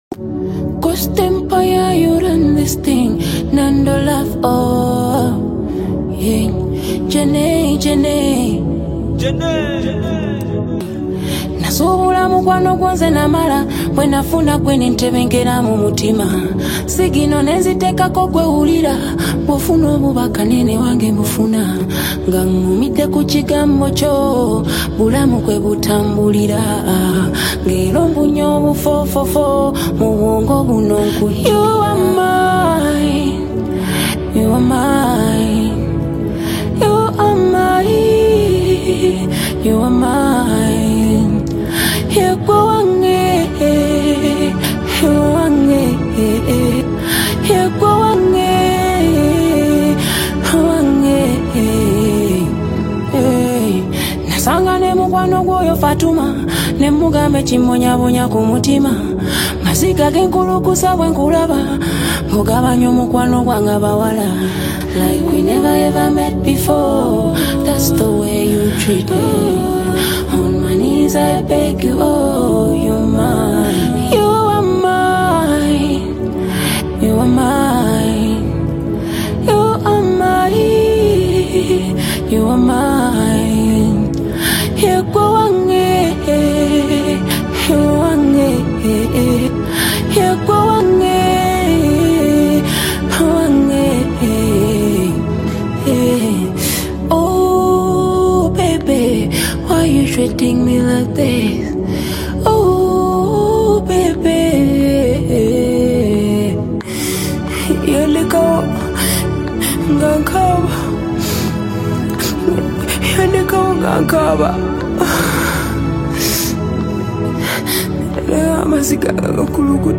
a fresh and emotional Afrobeats single
catchy rhythms and compelling lyricism